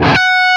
LEAD F#4 LP.wav